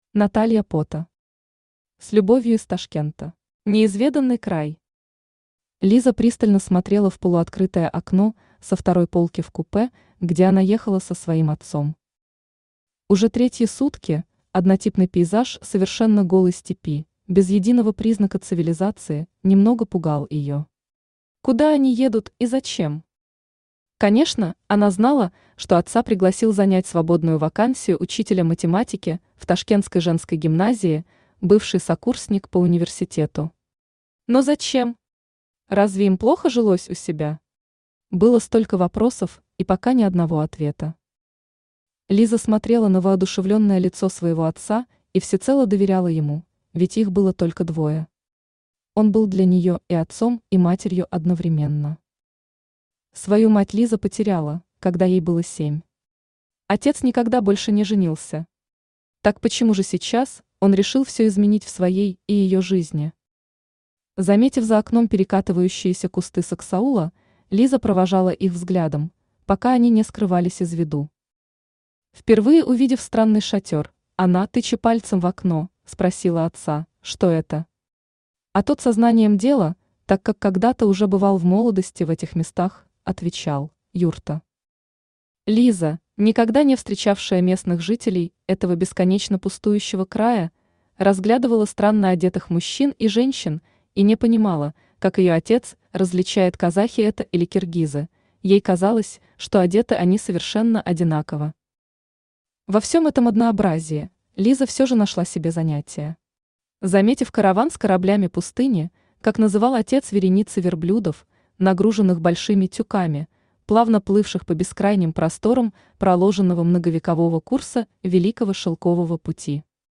Аудиокнига С любовью из Ташкента | Библиотека аудиокниг
Aудиокнига С любовью из Ташкента Автор Наталья Потто Читает аудиокнигу Авточтец ЛитРес.